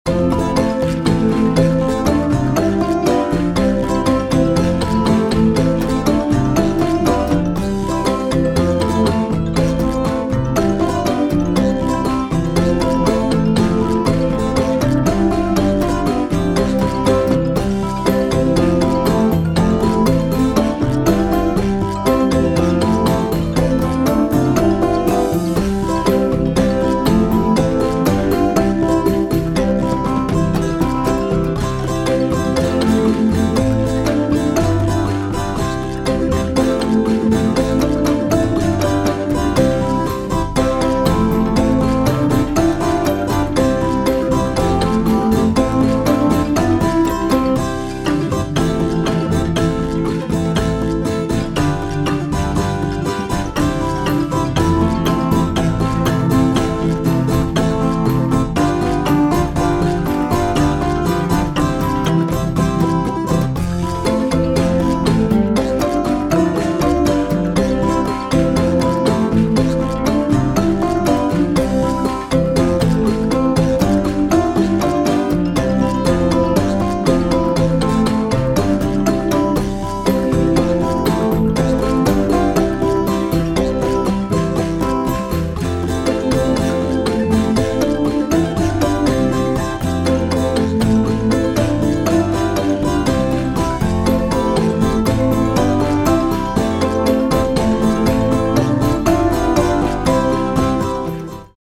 midi-demo 1
Tekst Nis Petersen